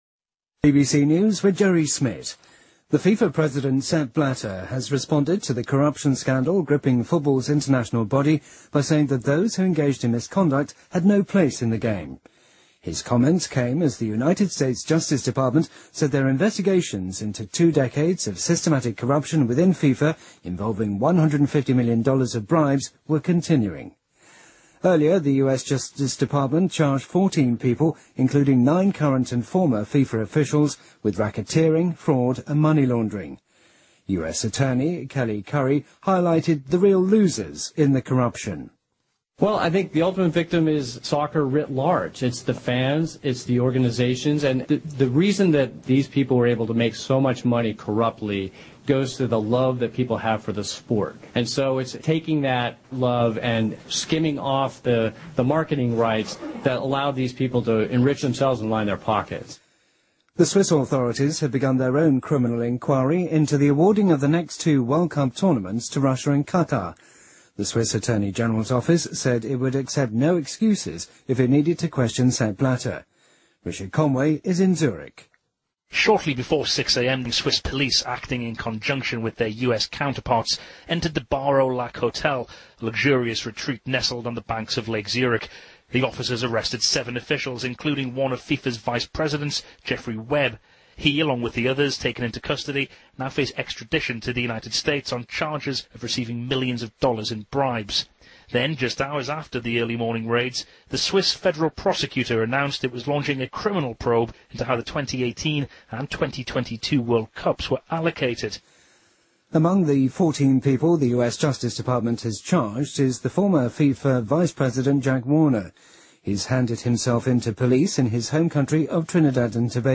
BBC news,FIFA主席布拉特回应腐败丑闻